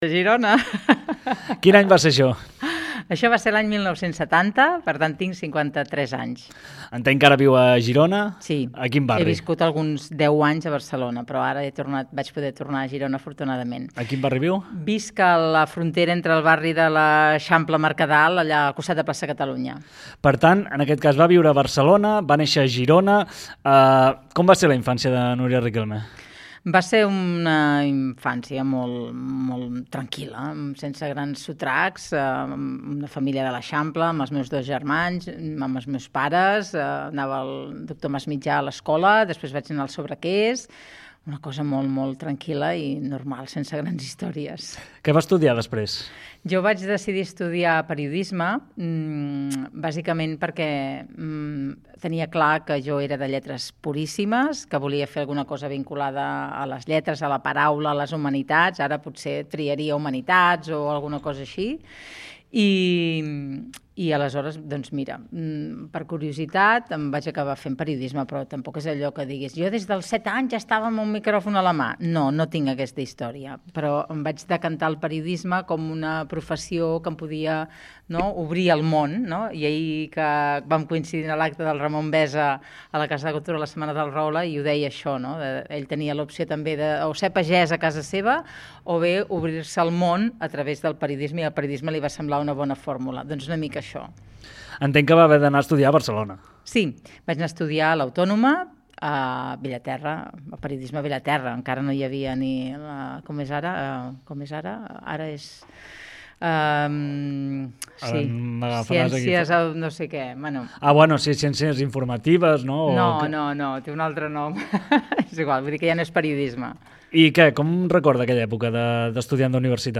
Entrevista personal a la Regidora de Servei a les Persones i Llengua catalana Núria Riquelme a GironaFM